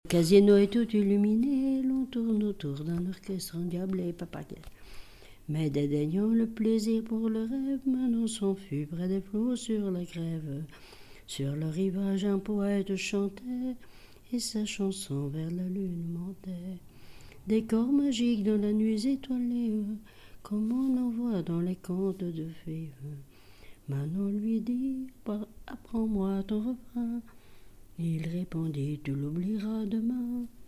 chanteur(s), chant, chanson, chansonnette ; amour(s), amourettes
témoignage et chansons
Pièce musicale inédite